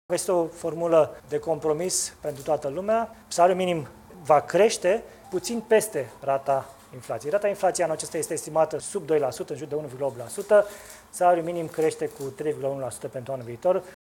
Este o soluție de compromis, a spus premierul Florin Cîțu, pe care îl puteți asculta aici: